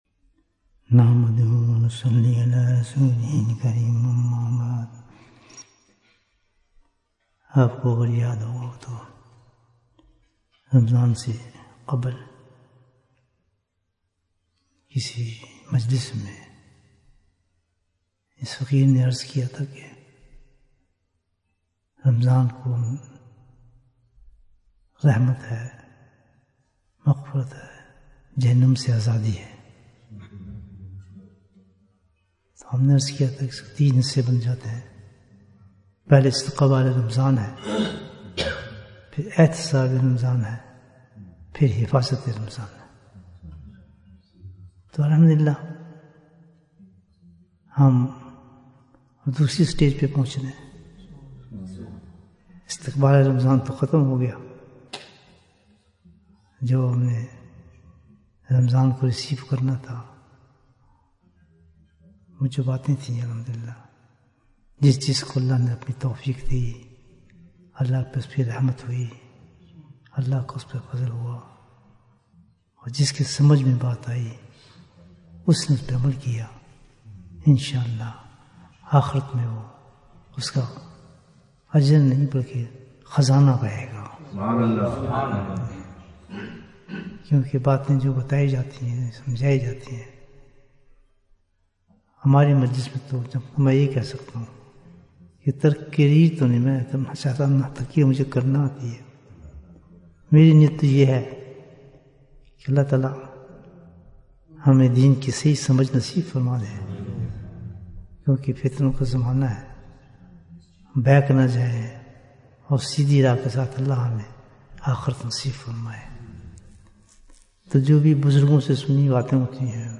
Bayan, 61 minutes 9th March, 2025 Click for English Download Audio Comments Bayan Episode 11 - Did You do Your Accounts in Ramadhan? Guests are a form of mercy, so we should value guests.